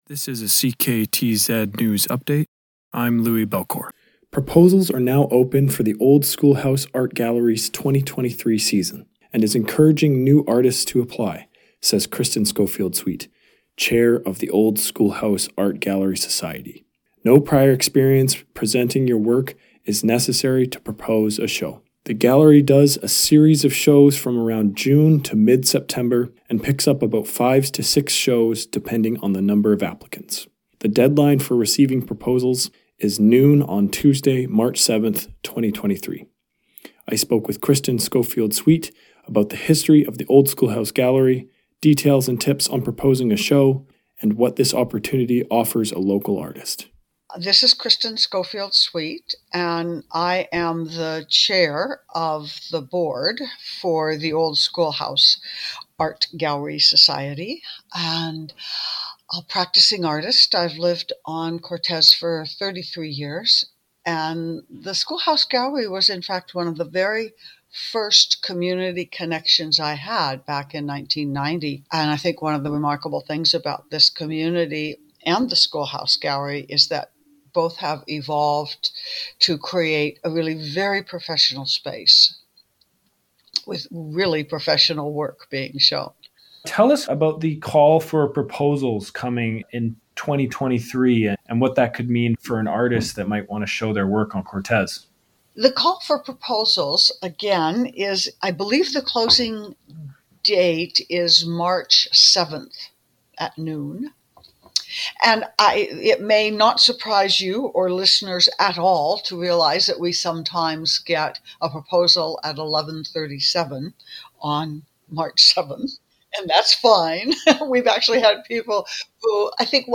shorterGALLERY-INTERVIEW-FINAL-FINAL-1.mp3